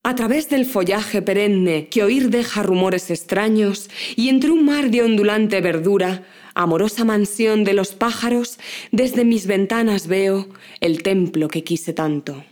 Muestras de audio codificadas con los principales códecs para voz
poema.wav